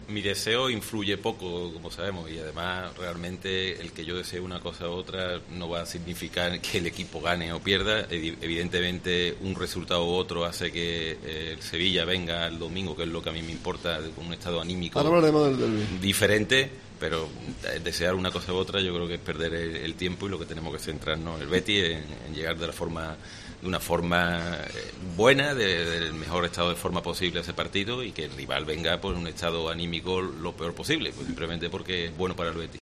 Deportes Cope Sevilla ha vuelto a disfrutar de un programa desde Sanlúcar de Barrameda que ya se ha convertido en una tradición.